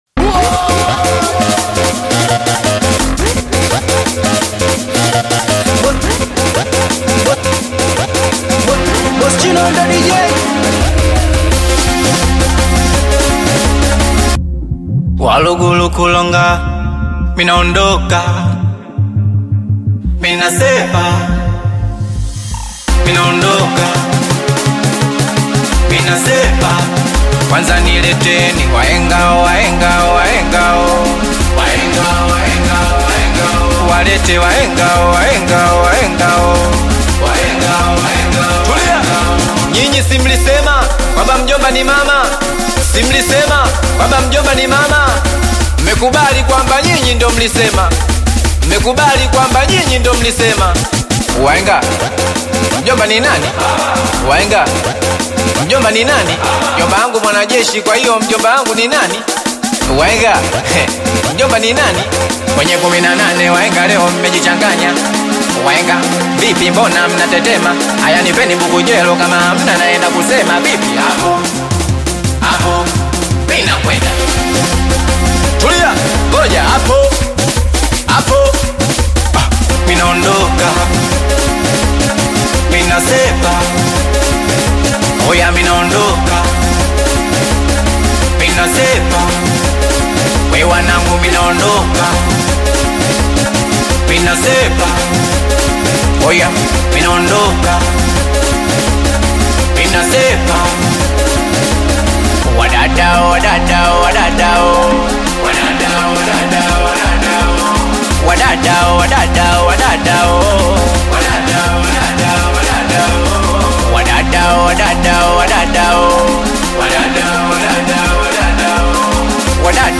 blends catchy melodies with modern production